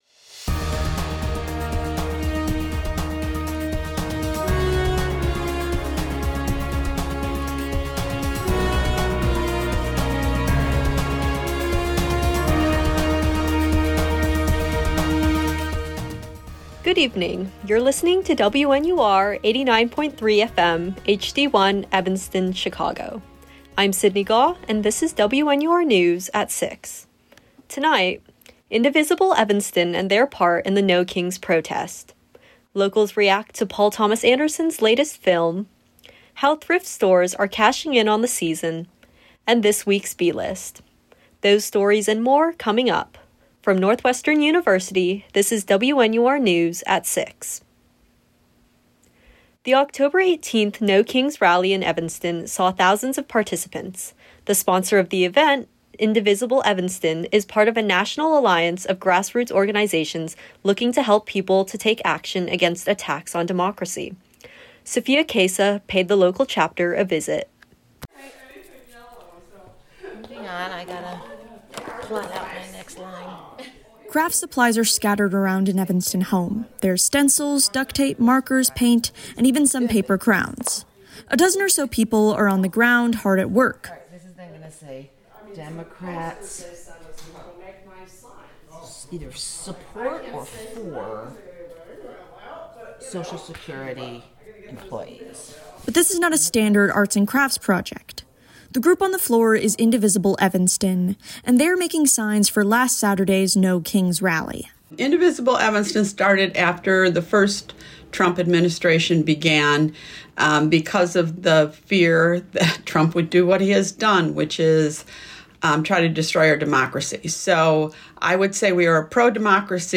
October 20, 2025: Indivisible Evanston, One Battle After Another, Thrift Shops during Fall, and the B-List. WNUR News broadcasts live at 6 pm CST on Mondays, Wednesdays, and Fridays on WNUR 89.3 FM.